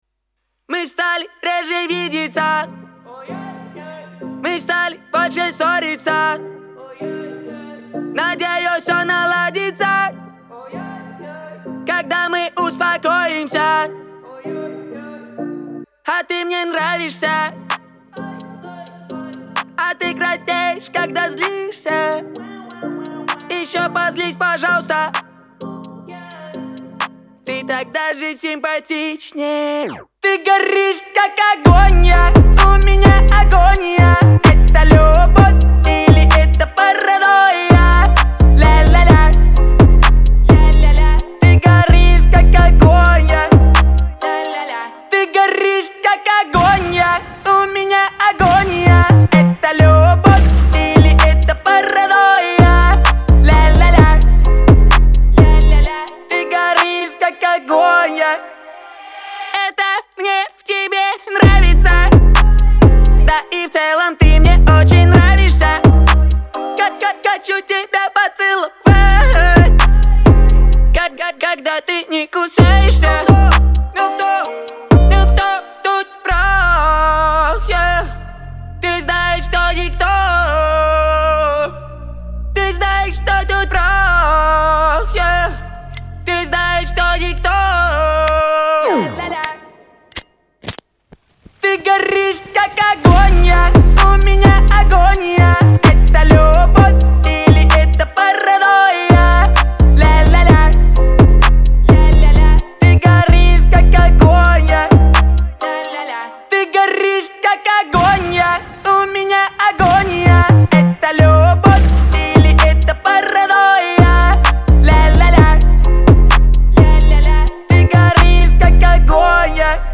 в жанре поп